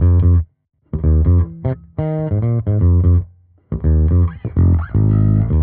Index of /musicradar/dusty-funk-samples/Bass/85bpm
DF_JaBass_85-F.wav